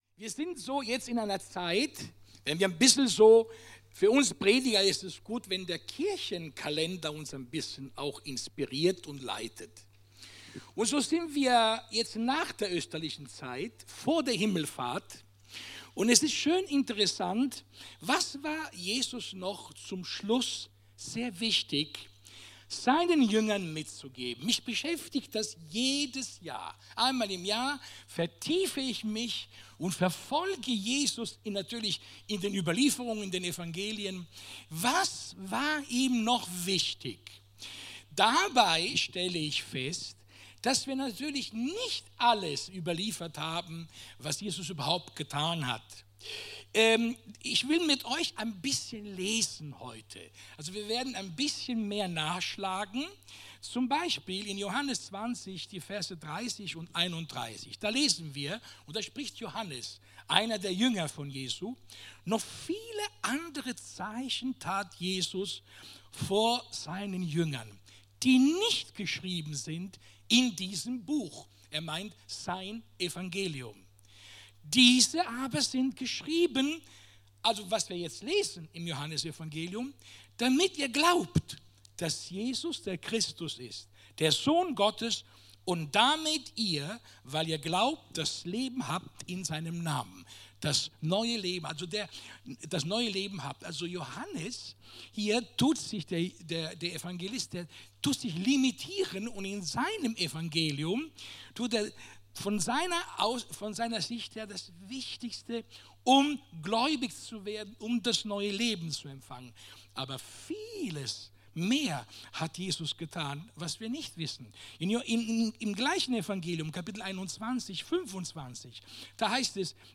Timotheus 1, 7; Jakobus 1, 5-8; Matthäus 28, 18-20; Apostelgeschichte 1, 1-3; Matthäus 26, 32 Dienstart: Sonntag « Love God.